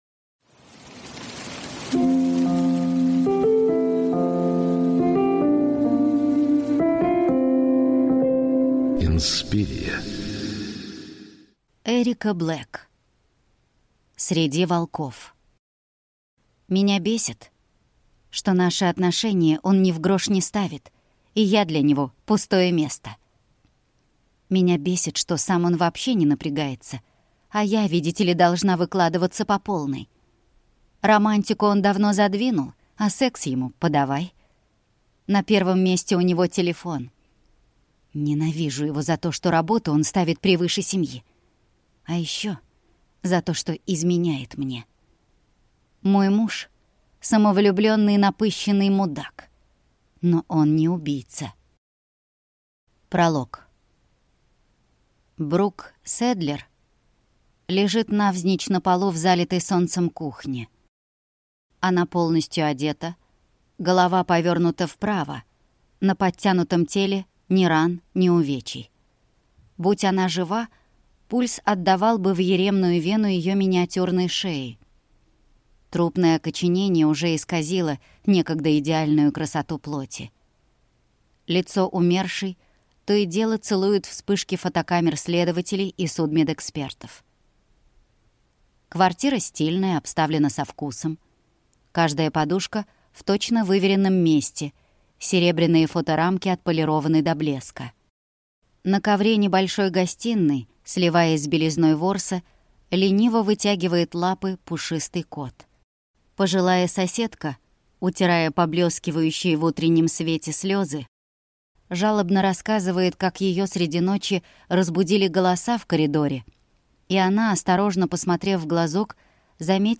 Аудиокнига Среди волков | Библиотека аудиокниг
Прослушать и бесплатно скачать фрагмент аудиокниги